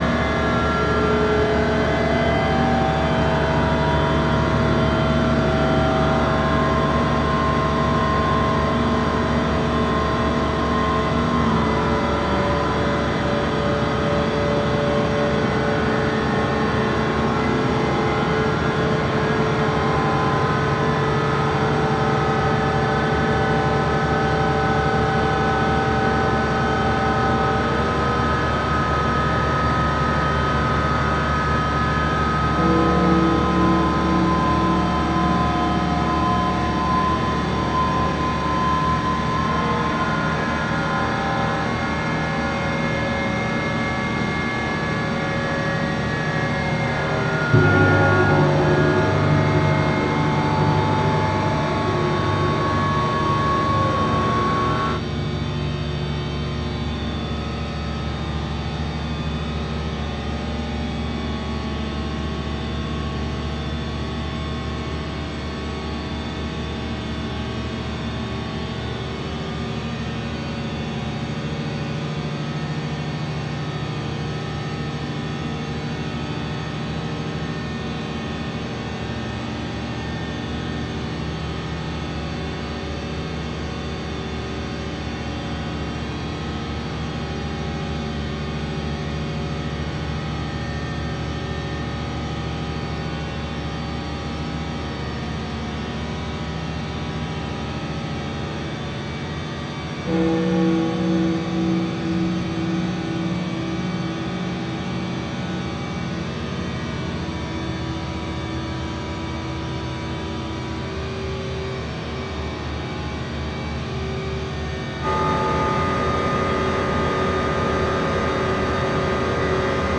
dense and noisy.
• Genre: Drone / Experimental / Noise